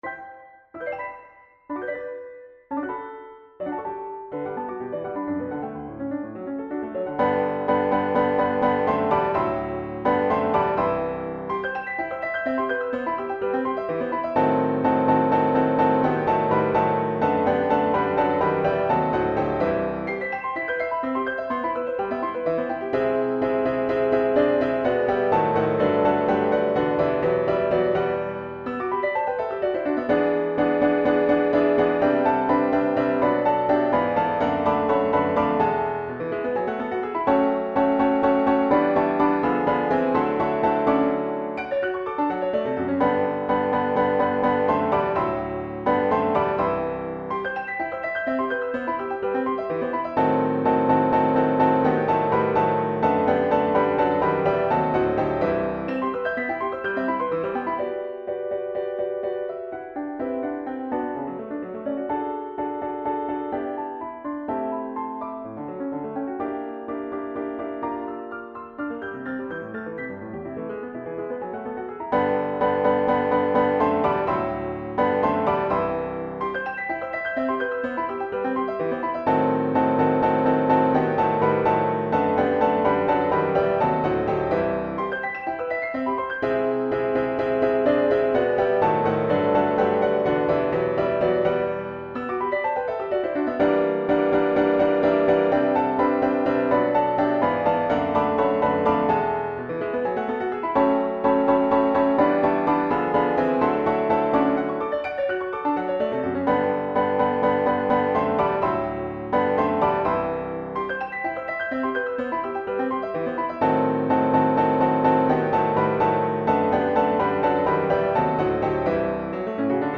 Instrumentation Piano solo